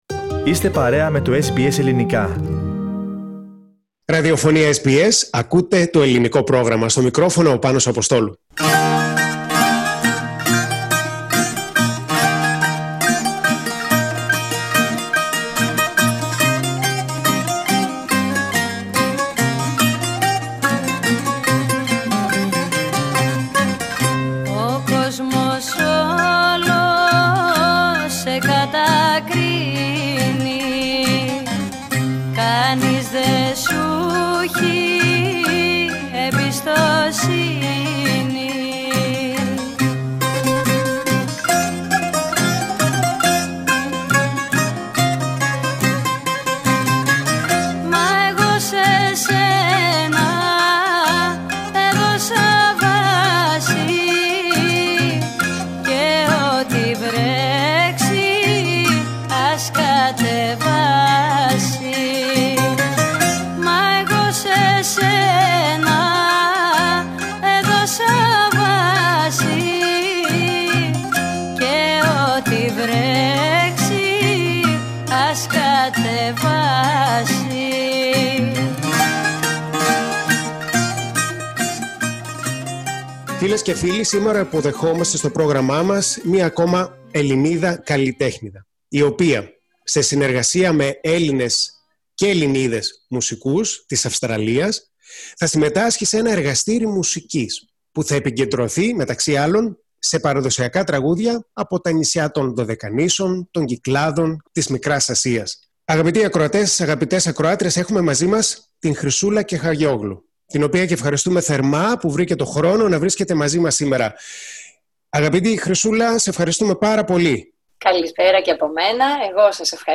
Greek singer